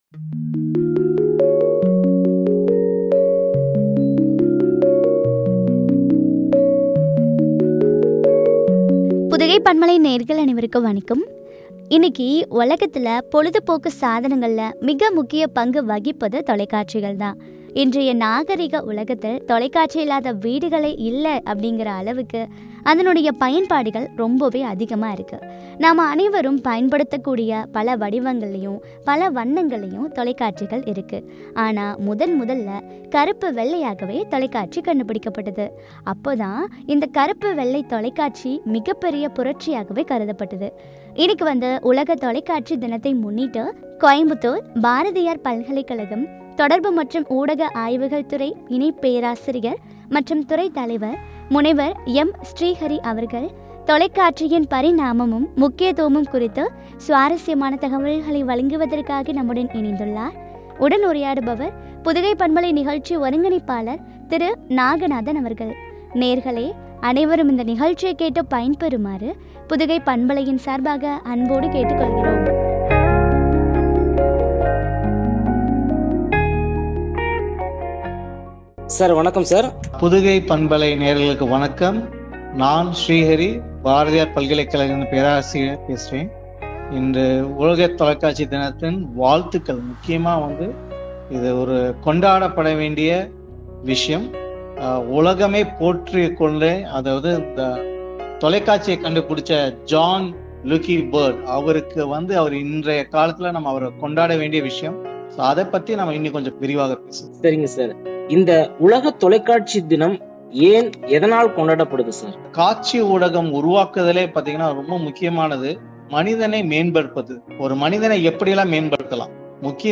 முக்கியத்துவமும் குறித்த விளங்கிய உரையாடல்.